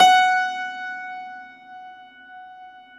53a-pno16-F3.wav